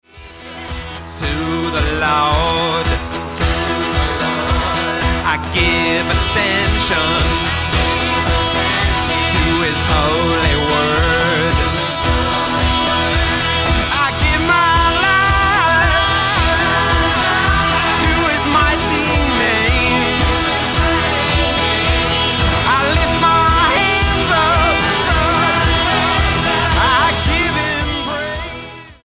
STYLE: Childrens